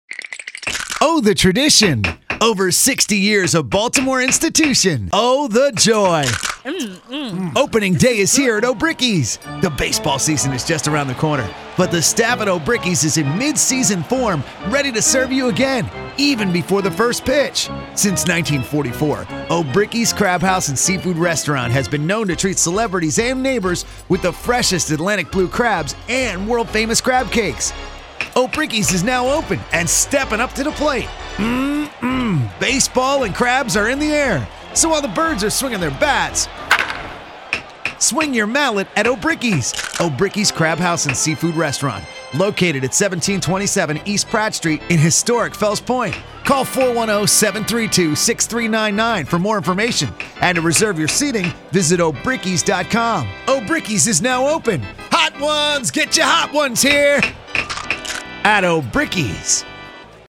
Obryckis Radio Commercial
Obryckisopeningday.mp3